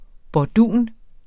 bordun substantiv, fælleskøn Bøjning -en, -er, -erne Udtale [ bɒˈduˀn ] Oprindelse af italiensk bordone 'basstemme, bordun', jævnfør fransk bourdon 'humlebi' Betydninger 1.